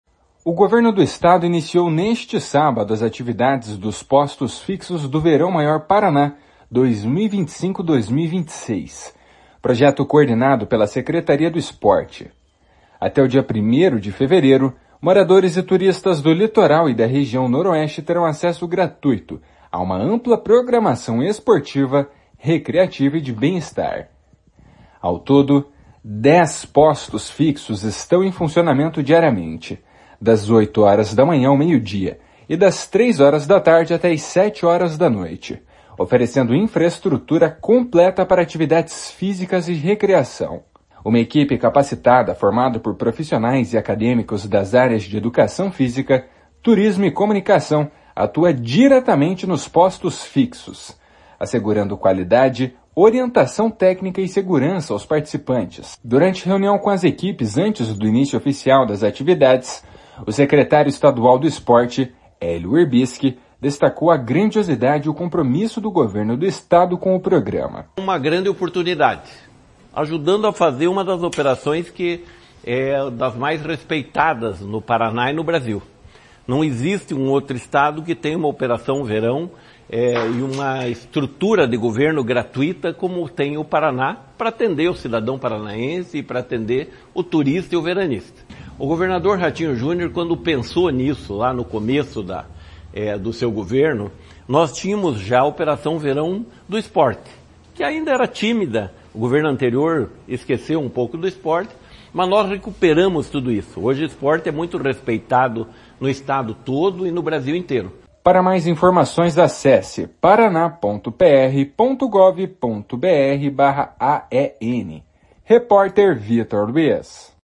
// SONORA HÉLIO WIRBISKI //